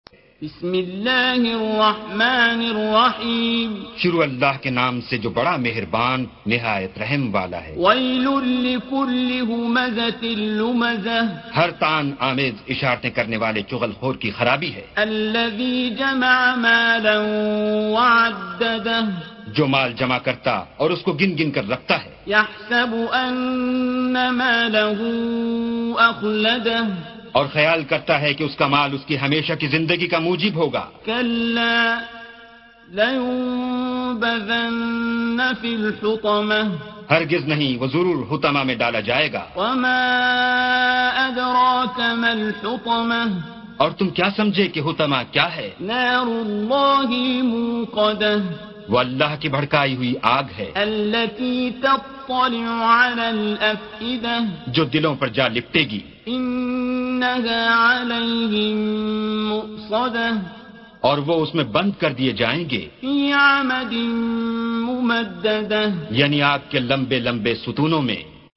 Surah Sequence تتابع السورة Download Surah حمّل السورة Reciting Mutarjamah Translation Audio for 104. Surah Al-Humazah سورة الهمزة N.B *Surah Includes Al-Basmalah Reciters Sequents تتابع التلاوات Reciters Repeats تكرار التلاوات